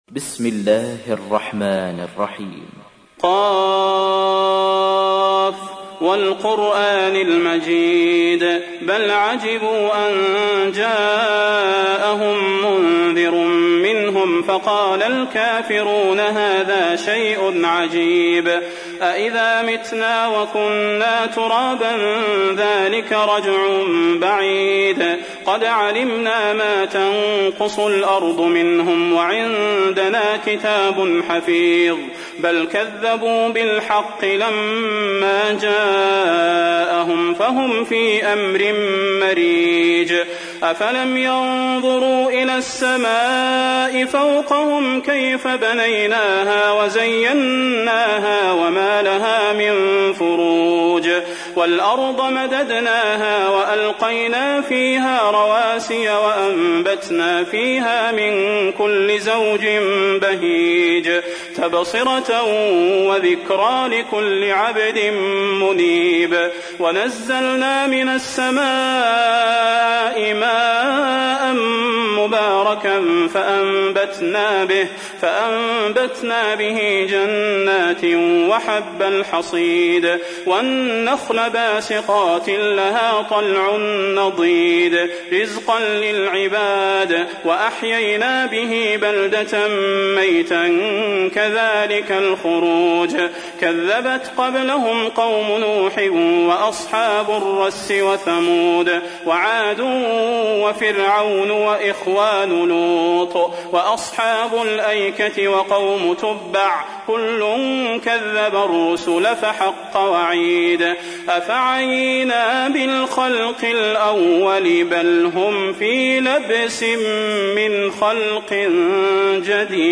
تحميل : 50. سورة ق / القارئ صلاح البدير / القرآن الكريم / موقع يا حسين